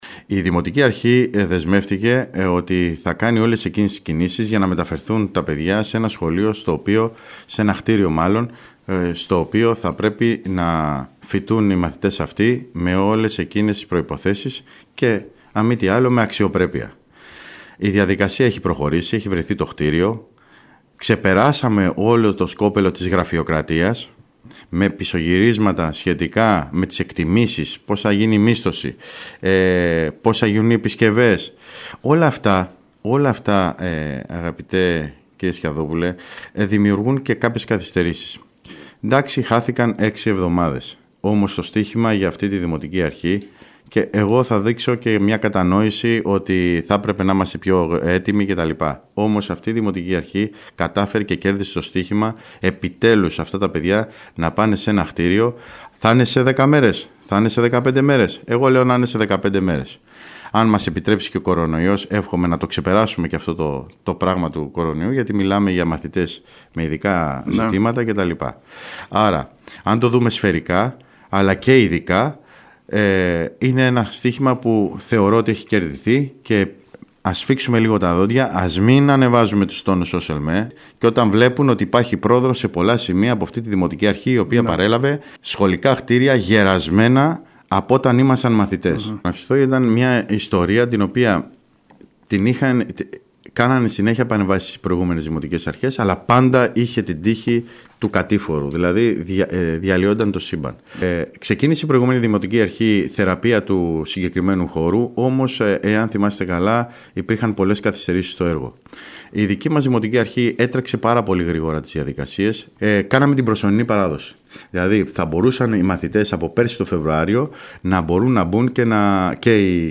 Τις δράσεις του Δήμου Κέρκυρας, με στόχο να προχωρήσει άμεσα η υλοποίηση τεχνικών παρεμβάσεων σε έργα, όπως το άλσος της Γαρίτσας, το θέατρο Φοίνικας, η οικία Γιαλινά, οι ποδηλατόδρομοι και σε έργα του οδικού δικτύου, παρουσίασε με συνέντευξή του στην ΕΡΤ Κέρκυρας ο Αντιδήμαρχος Τεχνικών Υπηρεσιών Νίκος Καλόγερος .